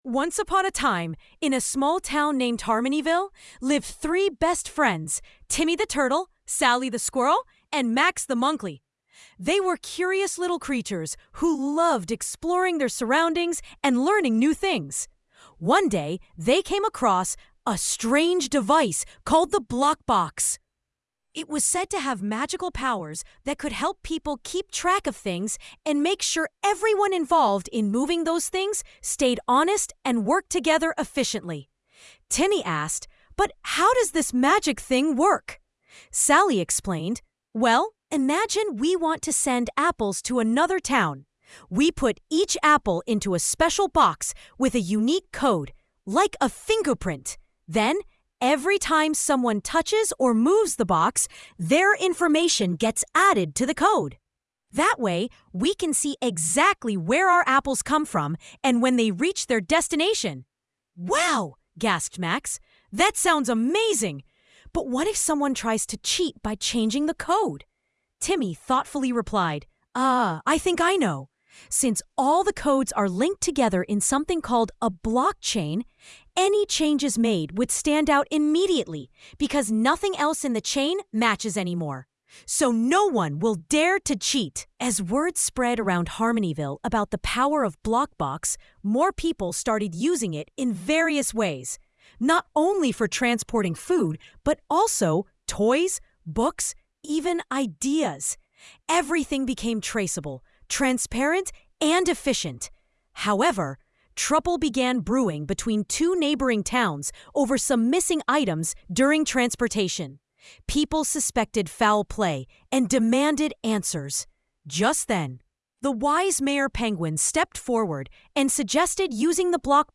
story
tts